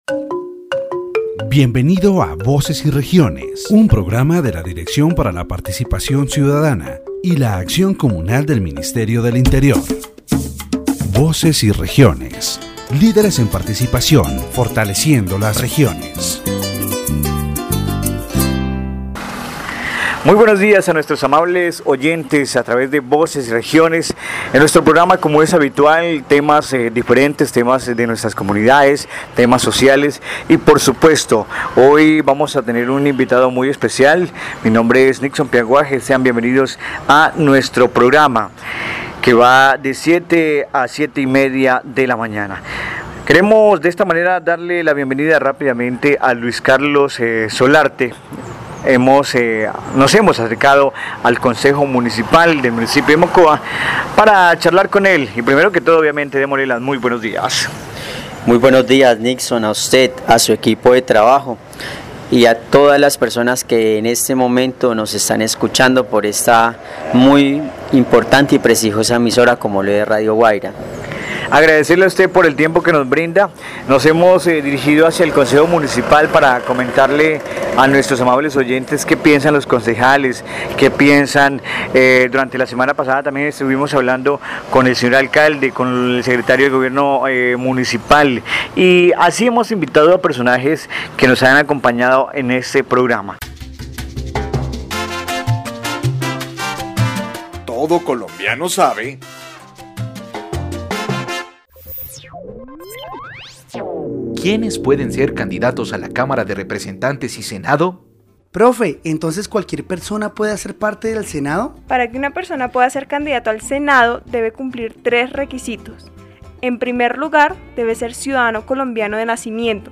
The program focuses on the problems of public space and mobility in the municipality of Mocoa. Through an interview with councilman Luis Carlos Solarte, topics such as: Non-compliance with traffic regulations: It is evident that despite the existence of decrees that regulate traffic and parking, these are not effectively enforced.